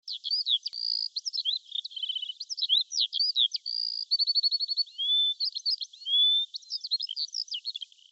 دانلود آهنگ جنگل 3 از افکت صوتی طبیعت و محیط
جلوه های صوتی
دانلود صدای جنگل 3 از ساعد نیوز با لینک مستقیم و کیفیت بالا